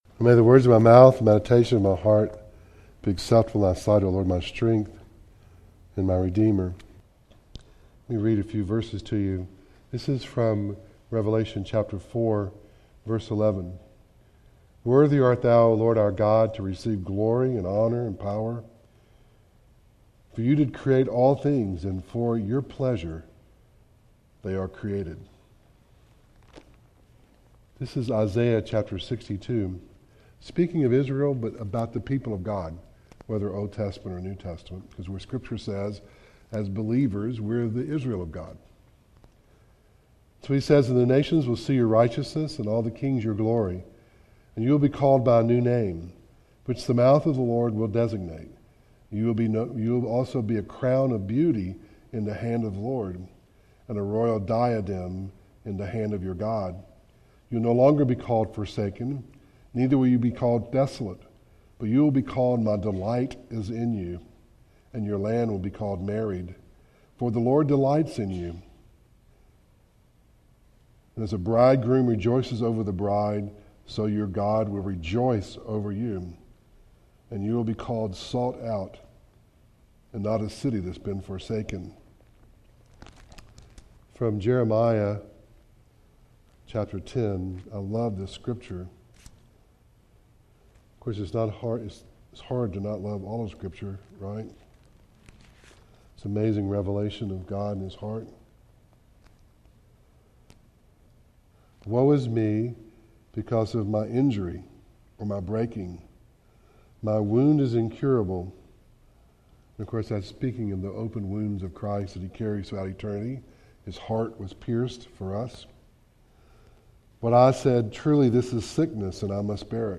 Acts 17:23-25 Service Type: Devotional